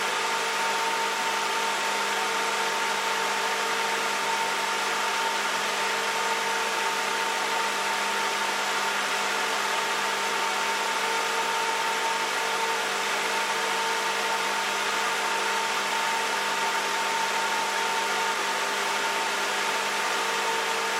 At full speed, the highest frequency is 1 KHz, so the output noise will be annoying.
I have recorded the signals shown above, but please keep in mind that I’ve enabled Automatic Gain Control (AGC) to do so to make it easier for you to reproduce them.
100% Fan Speed